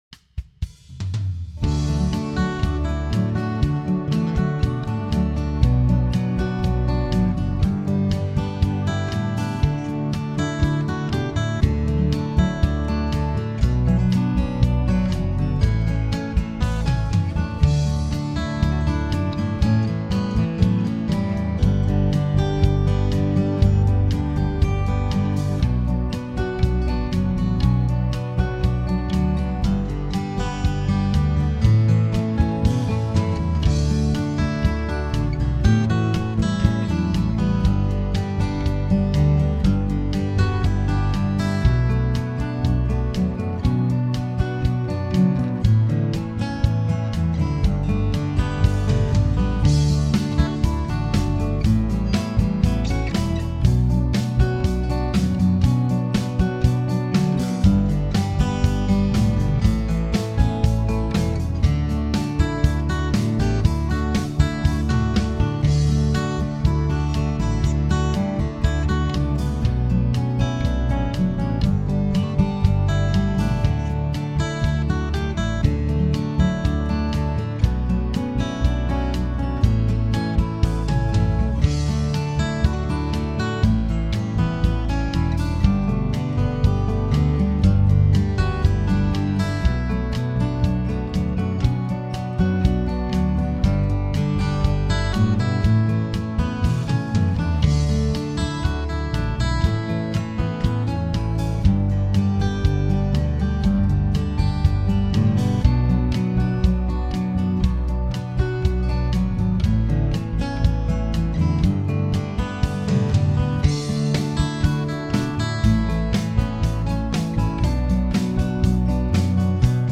Home > Music > Pop > Bright > Smooth > Medium